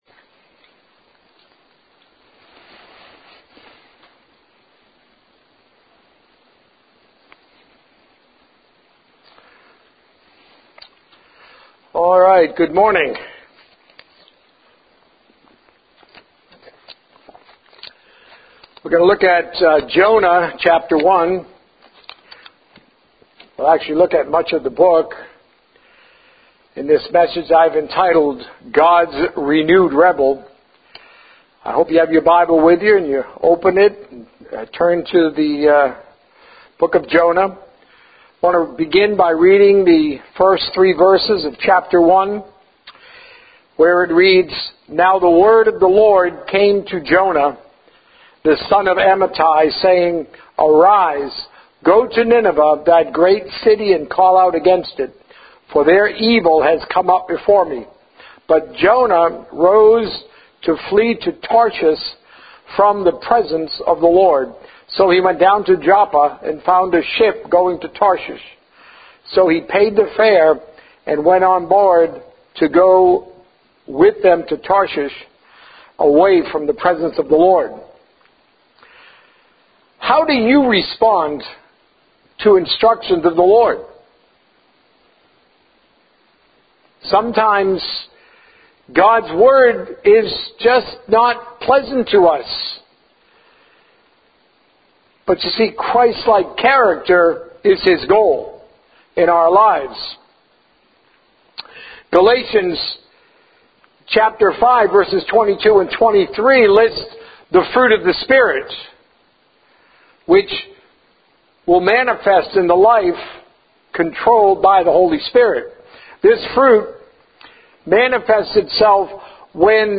A message from the series "Followers of Jesus."